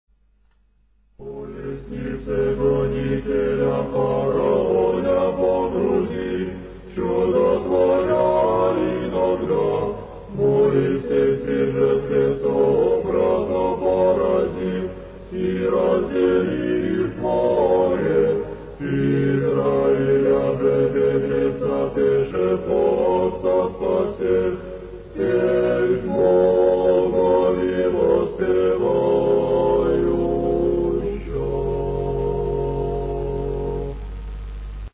24 сентября 2002 г. Перезаписаны с кассеты все файлы октоиха.
Ирмос